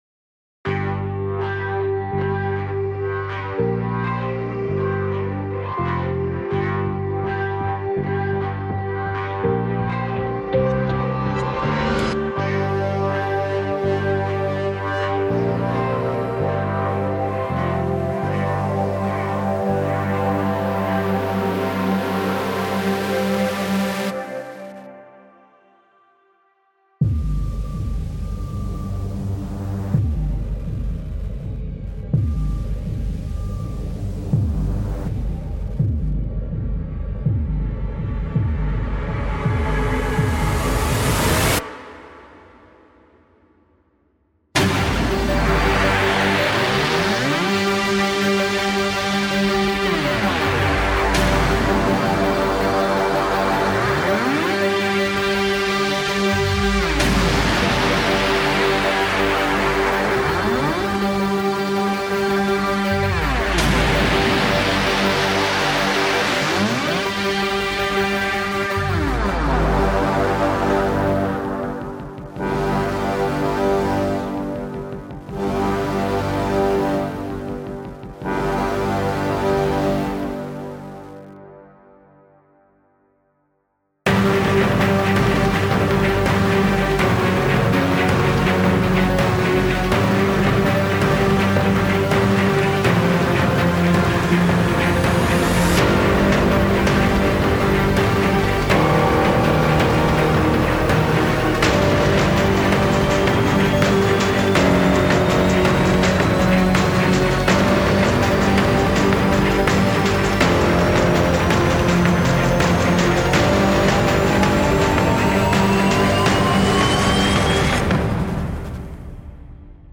Epic track for trailers.
Epic hybrid track for trailers.